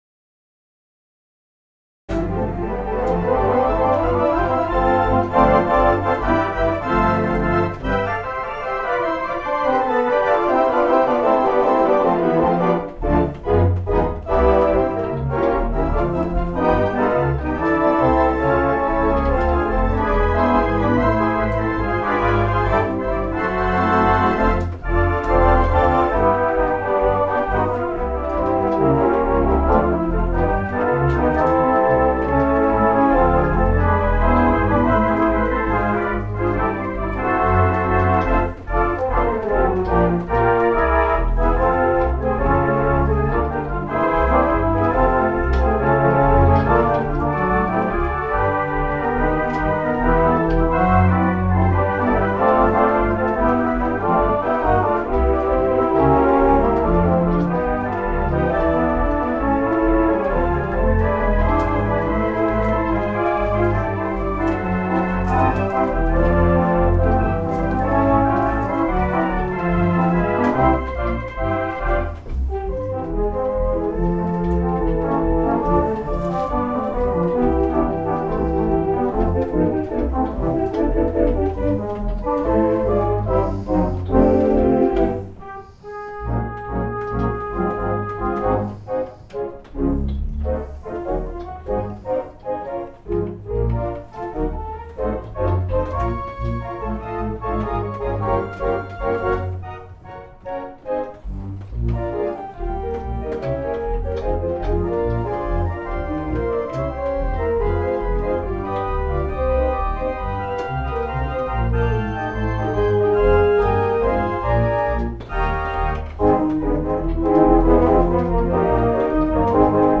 Theatre Pipe Organ
Mighty WurliTzer Theatre Pipe Organ